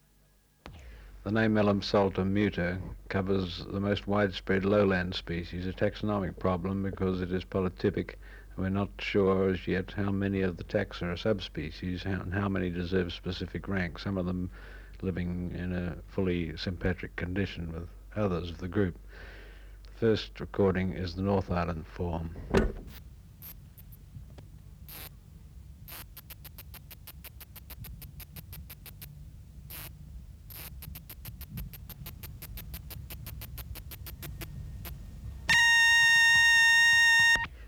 Reference Signal: 1 kHz at 12 dB at 1m at intervals
Recorder: Uher portable
05_Kikihia_muta_North_Island_form.wav